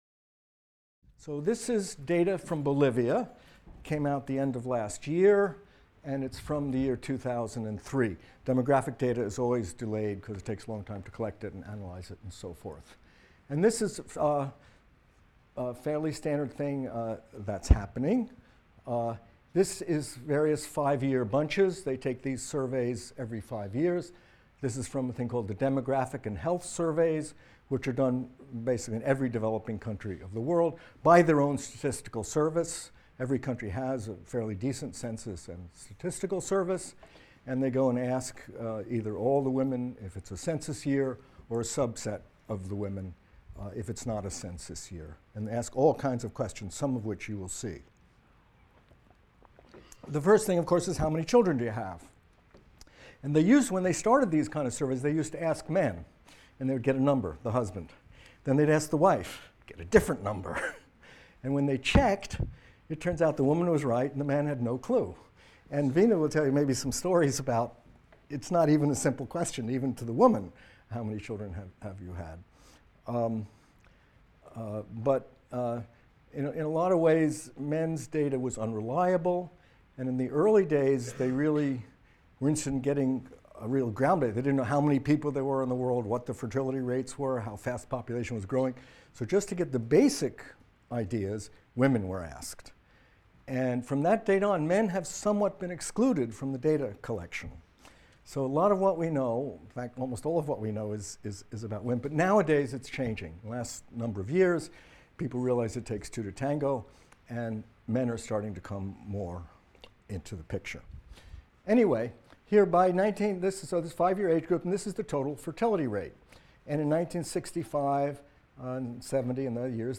MCDB 150 - Lecture 13 - Fertility Attitudes and Practices | Open Yale Courses